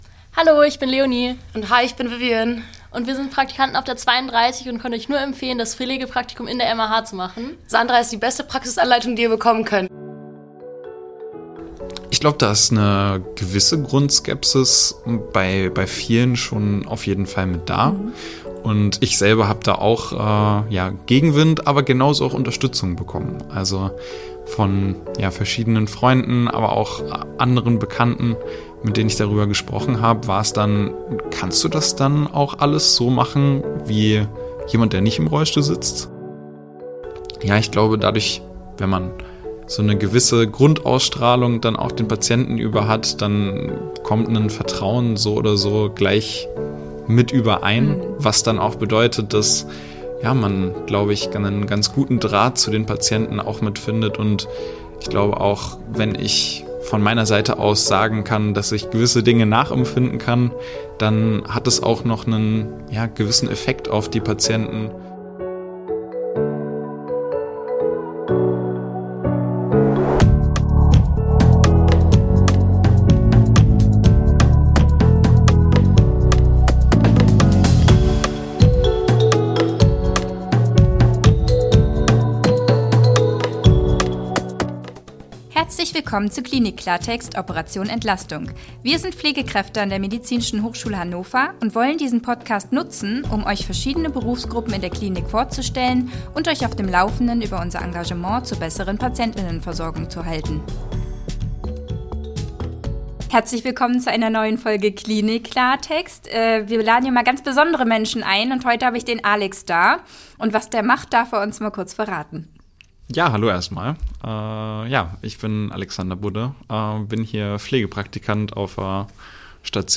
Pflegepraktikum Medizinstudium – Ein Interview